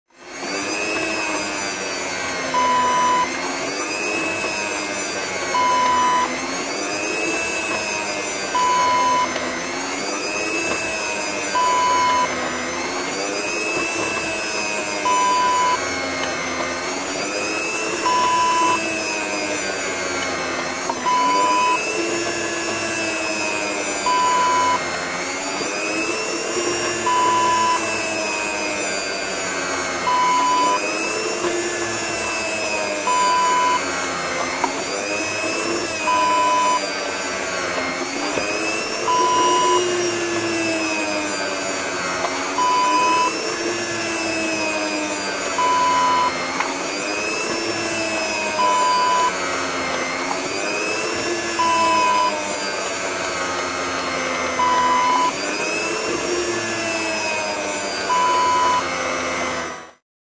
Kitchen Meat Slicer Sound Effect Wav
Description: Electric kitchen meat slicer slicing meat
Properties: 48.000 kHz 16-bit Stereo
A beep sound is embedded in the audio preview file but it is not present in the high resolution downloadable wav file.
meat-slicer-preview-1.mp3